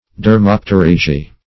Search Result for " dermopterygii" : The Collaborative International Dictionary of English v.0.48: Dermopterygii \Der*mop`te*ryg"i*i\, n. pl.
dermopterygii.mp3